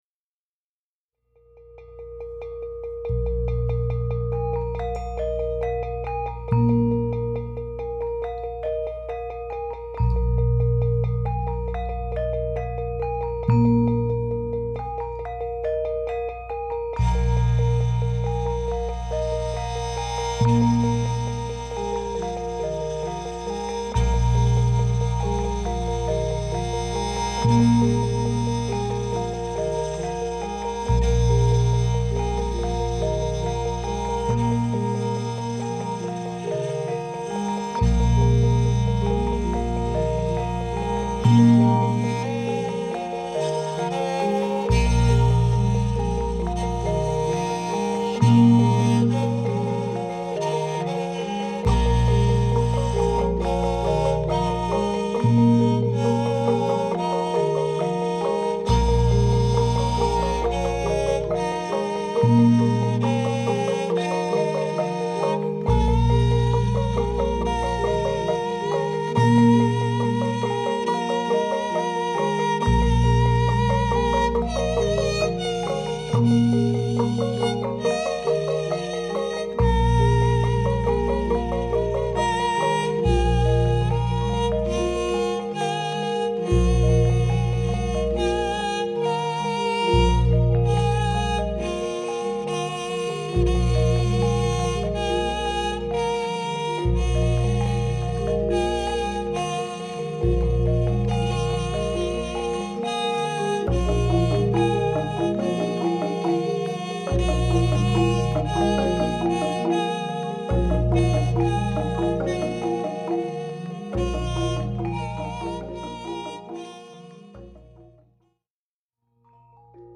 group improvisation